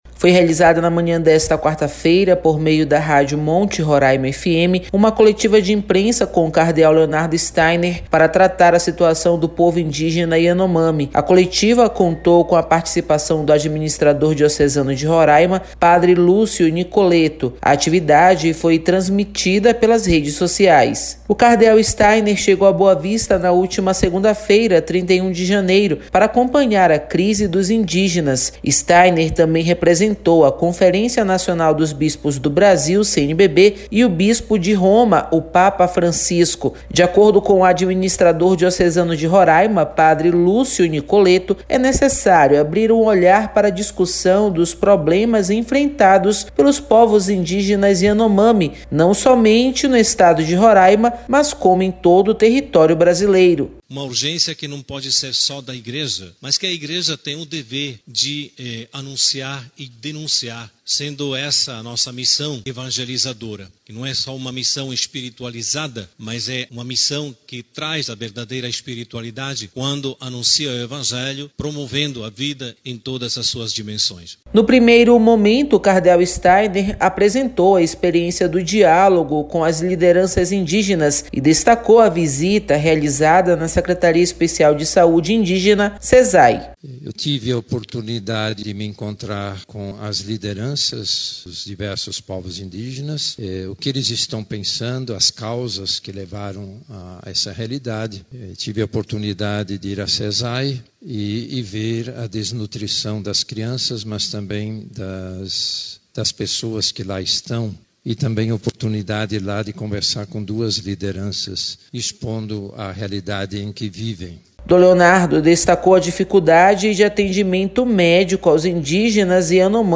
Cardeal Steiner concede coletiva de imprensa sobre a situação dos Yanomami
Foi realizada na manhã desta quarta-feira (01) por meio da Rádio Monte Roraima FM, uma coletiva de imprensa com o Cardeal Leonardo Steiner para tratar a situação do Povo Indígena Yanomami.